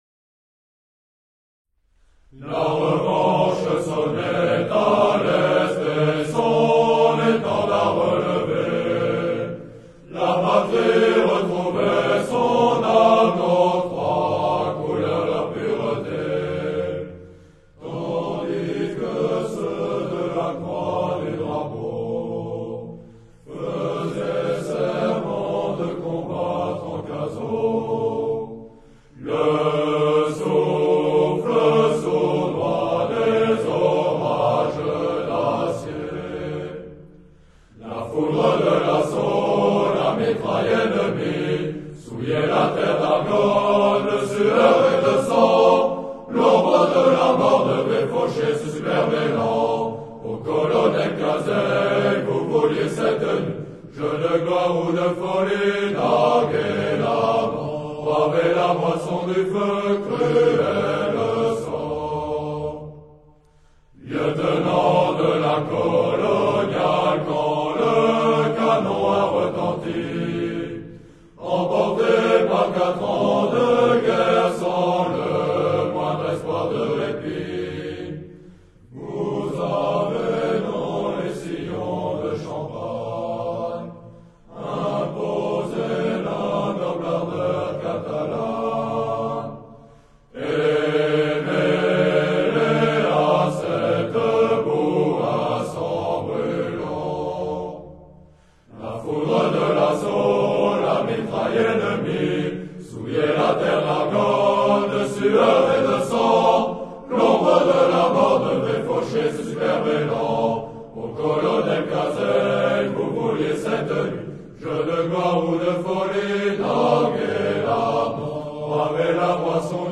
Colonel-Cazeilles-Chant-de-promotion-ESM-Saint-Cyr.mp3